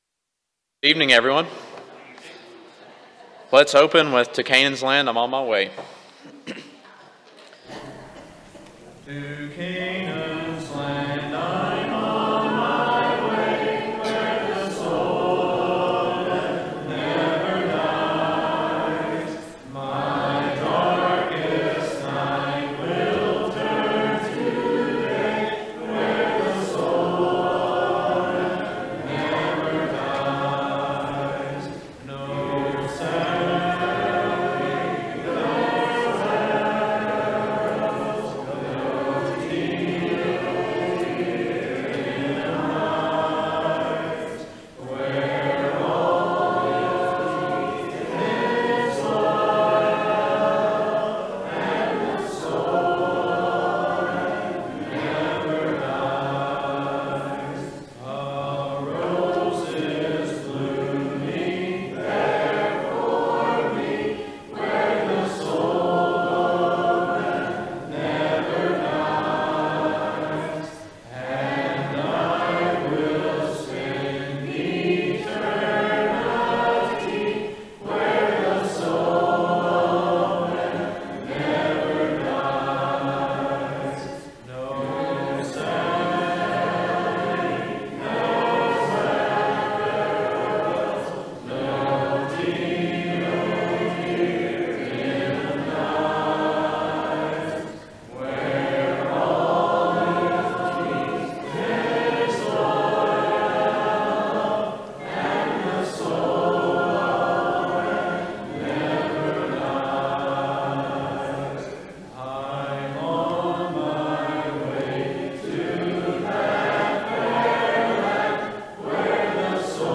Ephesians 4:19, English Standard Version Series: Sunday PM Service